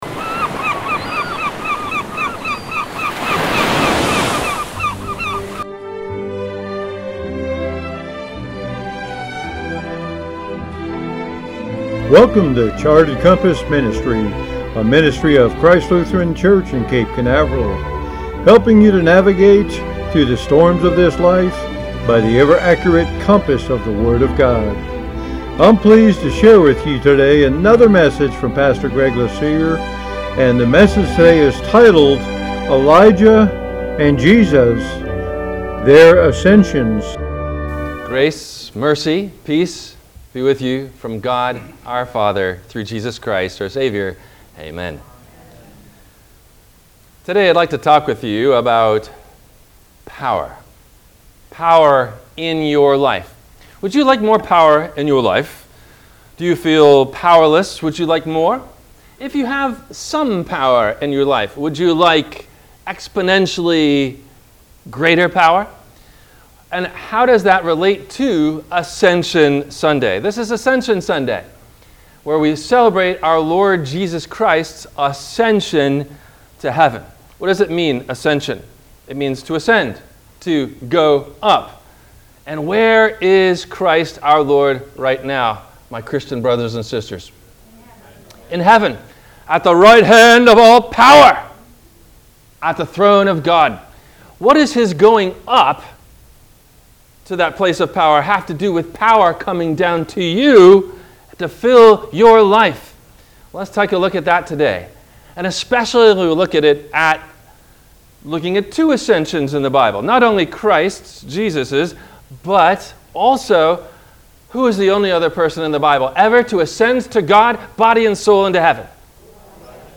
Elijah and Jesus – Their Ascensions – WMIE Radio Sermon – May 29 2023